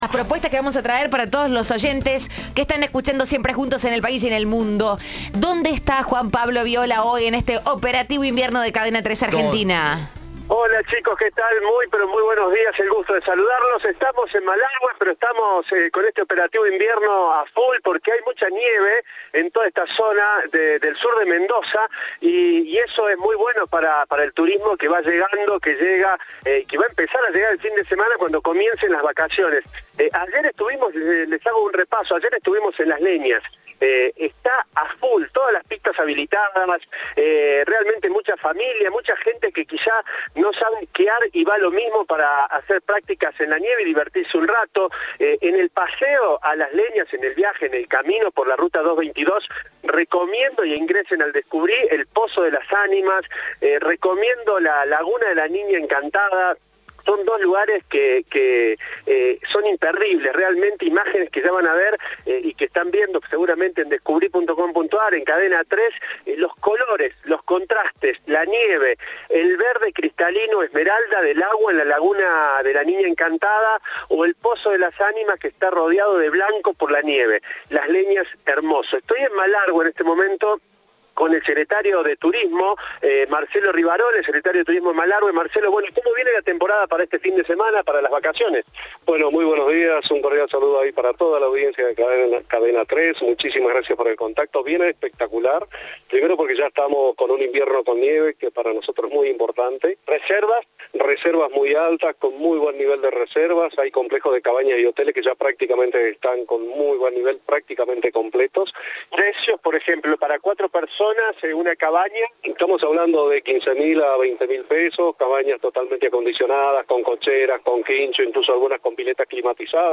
El secretario de Turismo, Marcelo Rivarola, habló con Cadena 3 y explicó todo lo que ofrece la localidad para el receso invernal.